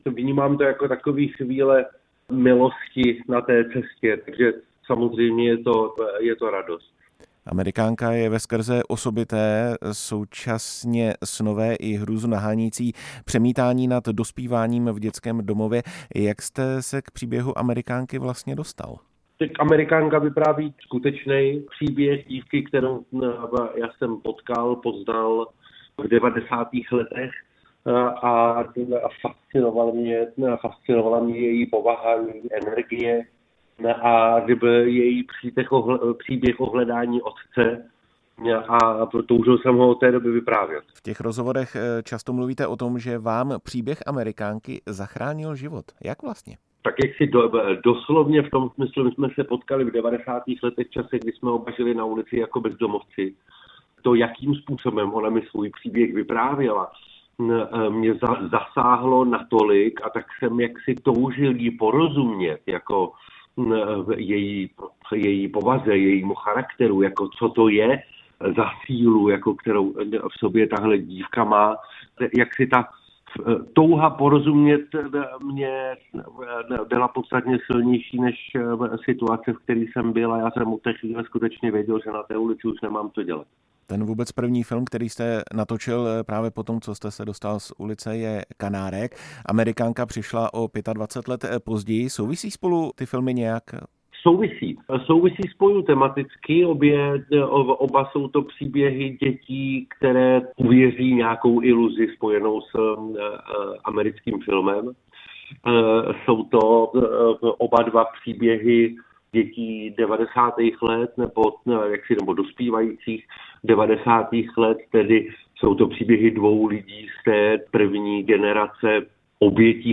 Viktor Tauš hostem vysílání Radia Prostor